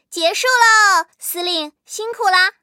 M18地狱猫战斗返回语音.OGG